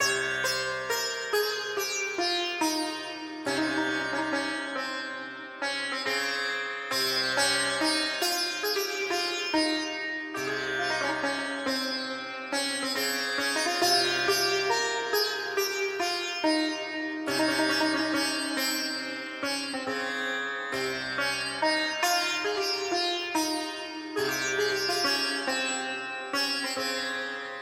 印度西塔琴类型的循环
描述：与印度鼓型循环和印度长笛一起使用
Tag: 139 bpm Trap Loops Sitar Loops 4.65 MB wav Key : Unknown